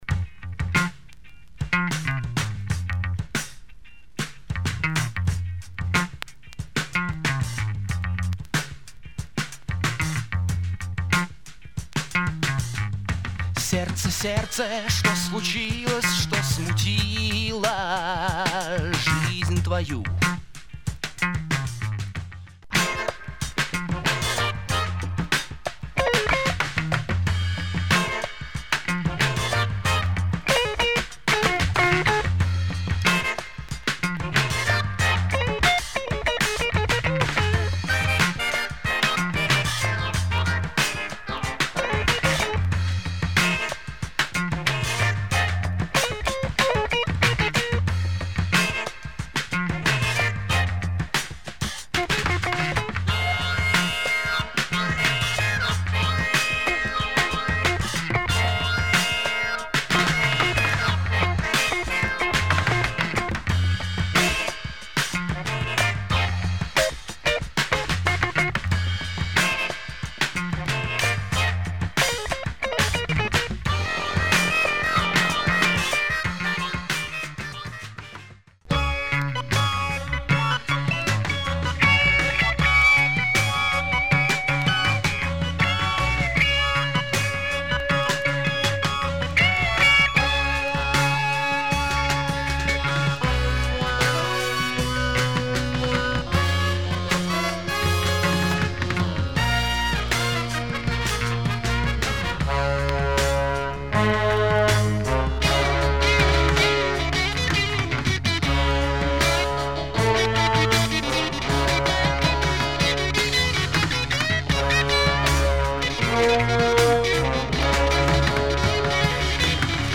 Interesting kinda prog funk album
Russian keyboard player